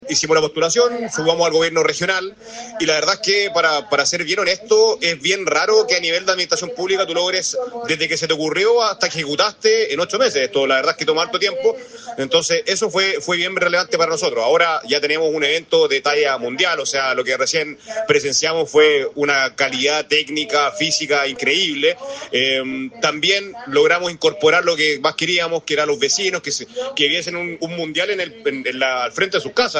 El jefe comunal destacó la coordinación entre las distintas instituciones y la posibilidad de incorporar a los vecinos para que vivieran un mundial en la puerta de sus casas.